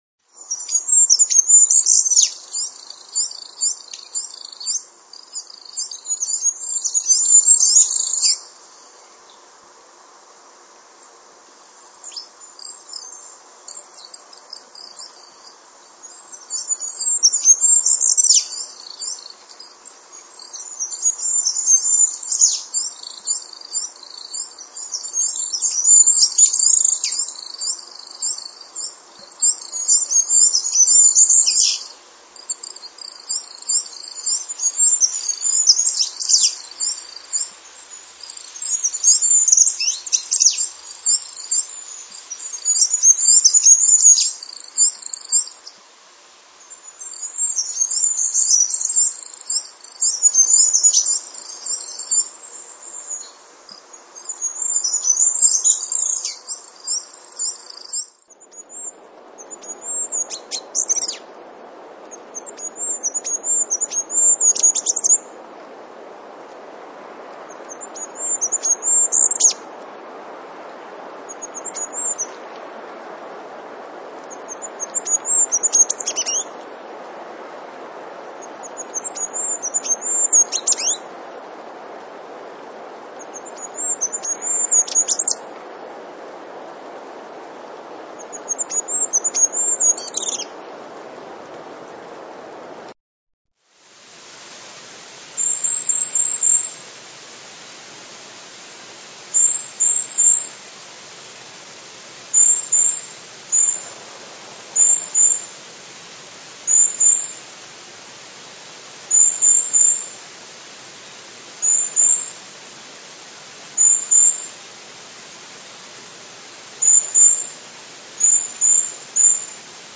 Przykłady śpiewu pełzaczy pochodzą z moich własnych nagrań.
Można wtedy usłyszeć ich wysokie, dźwięczne, jednosylabowe głosy, które jednak nie są śpiewem.
regulus_regulus.mp3